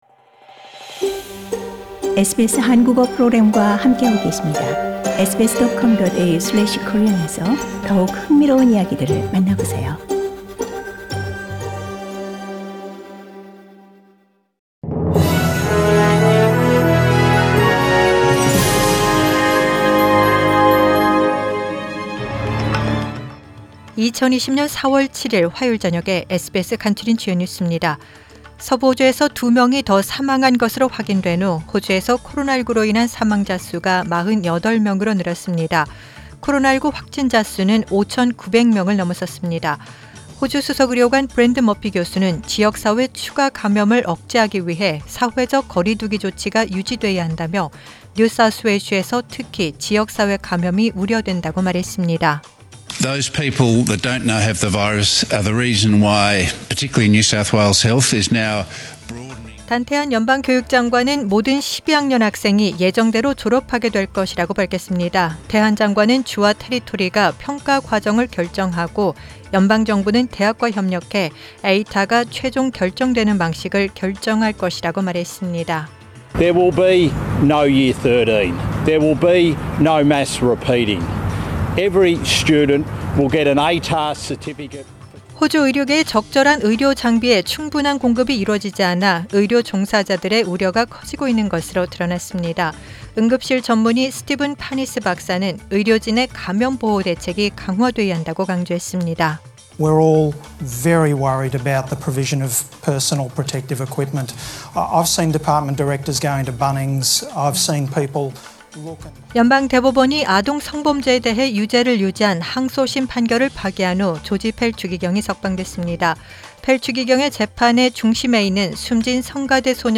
Major stories from SBS Korean News on Tuesday, 07 April